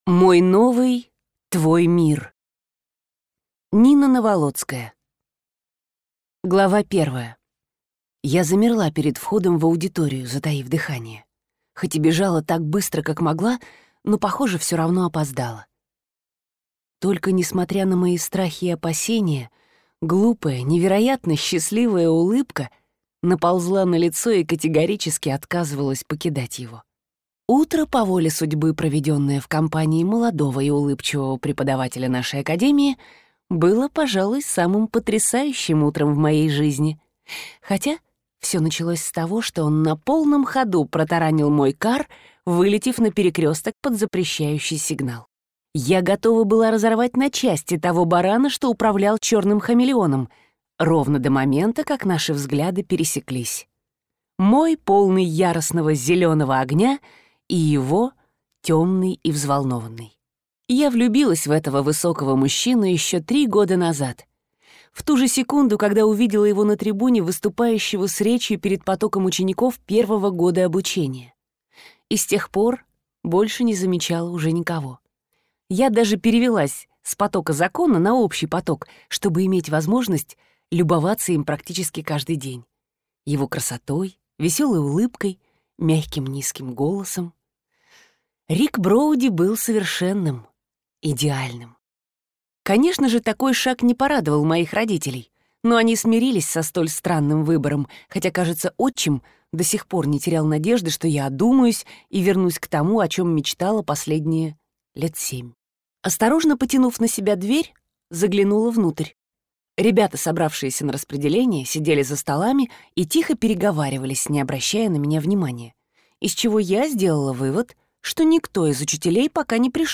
Аудиокнига Мой новый твой мир | Библиотека аудиокниг